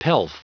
Prononciation du mot pelf en anglais (fichier audio)